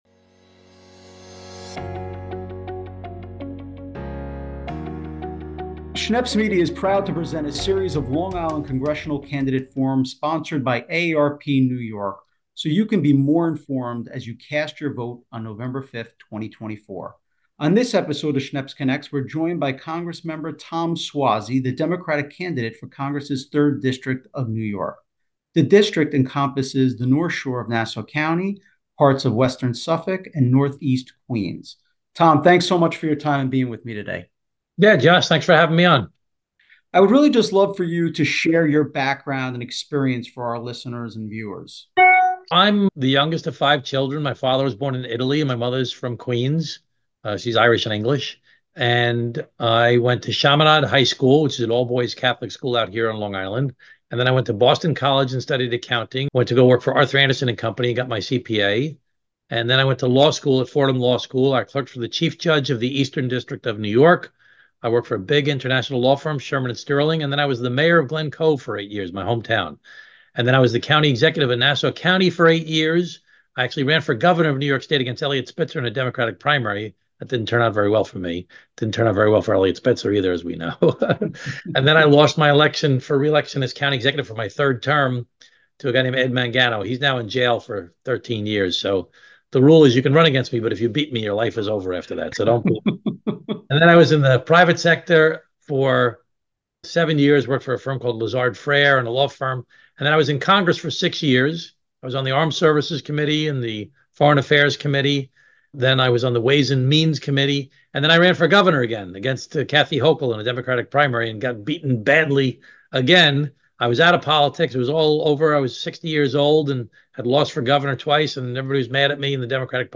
Schneps Connects a conversation with NY-3 Congressman Tom Suozzi